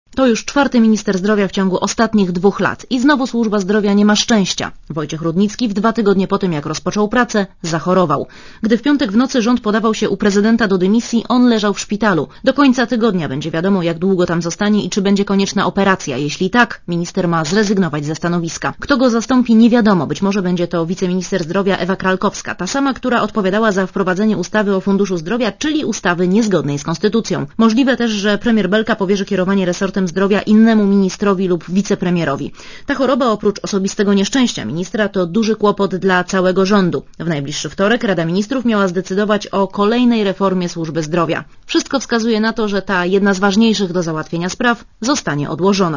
Posłuchaj relacji reporterki Radia ZET (178 KB)